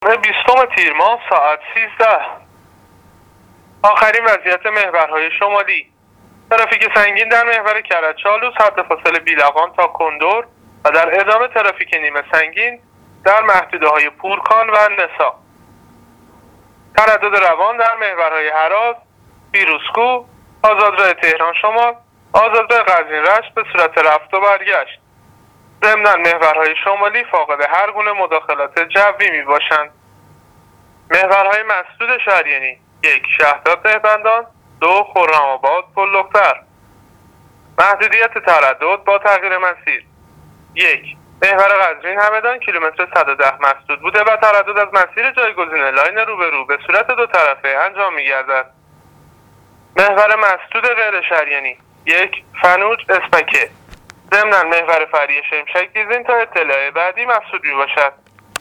گزارش رادیواینترنتی از وضعیت ترافیکی جاده‌ها تا ساعت ۱۳ جمعه ۲۰ تیر